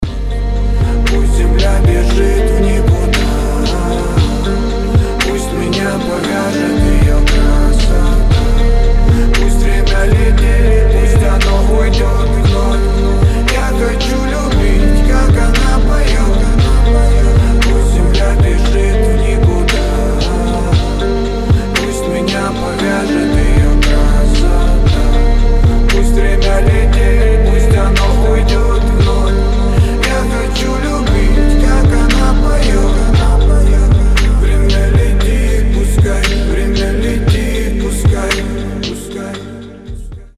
• Качество: 320, Stereo
Хип-хоп
мелодичные
спокойные
романтичные